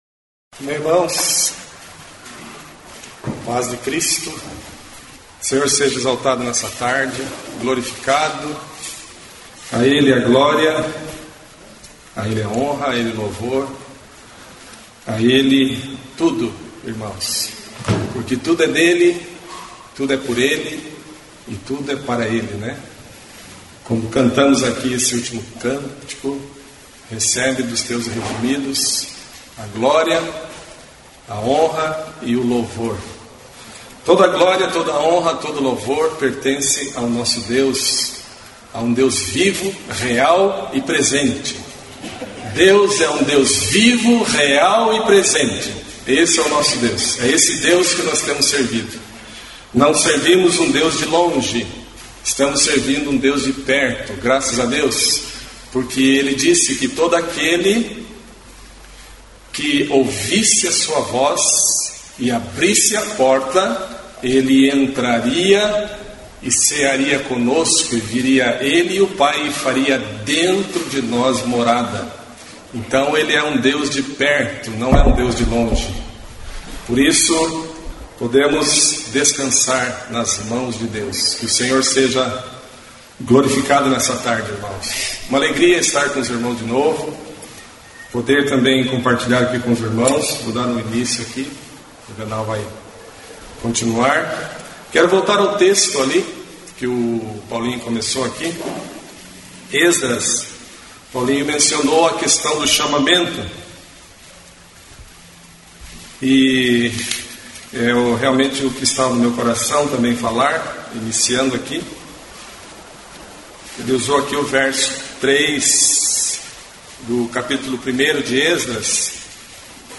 da reunião de homens de Curitiba, Morretes e Paranaguá em Curitiba no dia 04/06/2022.